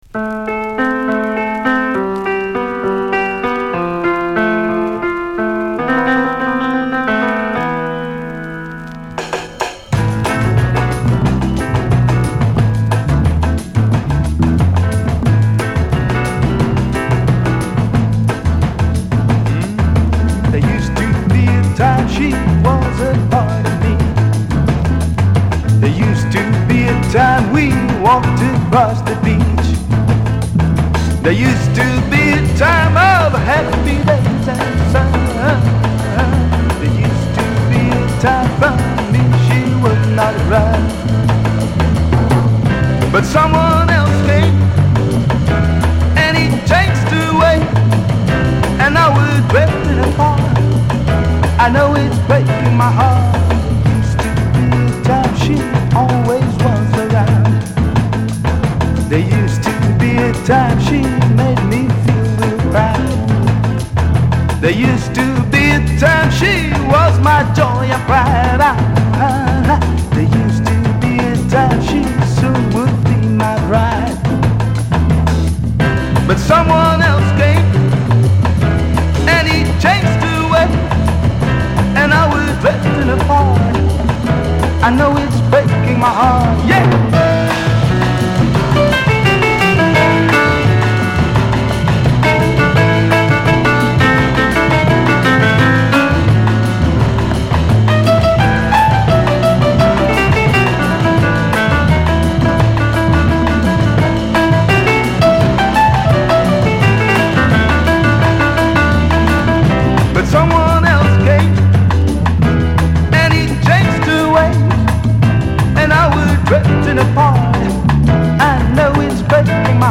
Rock / Pop spain
フォーキー且つジャジーな雰囲気がとても格好良い哀愁ナンバーです。